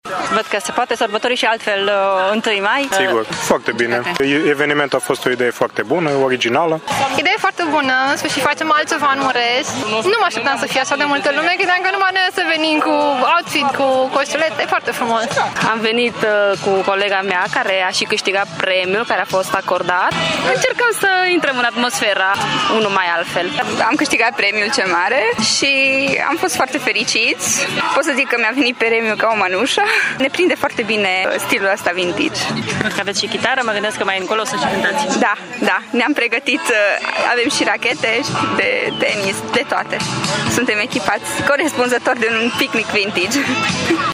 Oamenii au fost foarte fericiți că la Tîrgu-Mureș se organizează și astfel de evenimente: